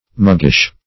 muggish - definition of muggish - synonyms, pronunciation, spelling from Free Dictionary Search Result for " muggish" : The Collaborative International Dictionary of English v.0.48: Muggish \Mug"gish\, a. See Muggy .